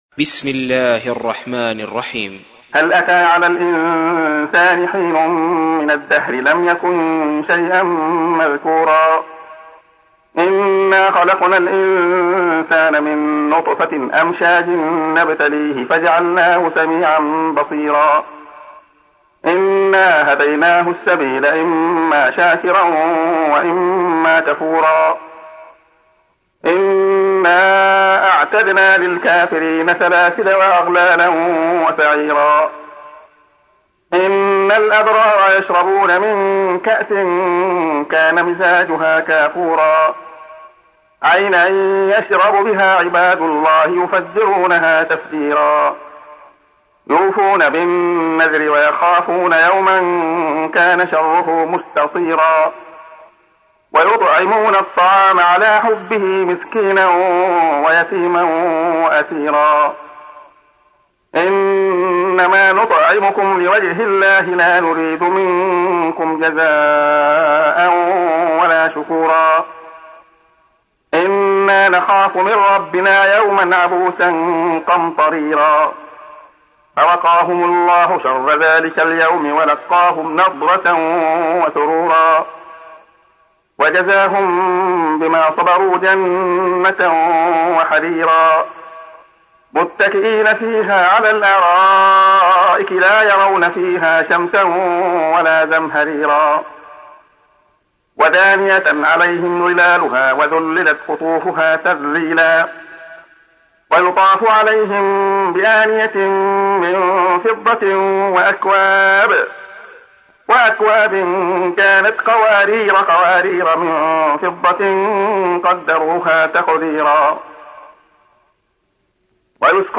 تحميل : 76. سورة الإنسان / القارئ عبد الله خياط / القرآن الكريم / موقع يا حسين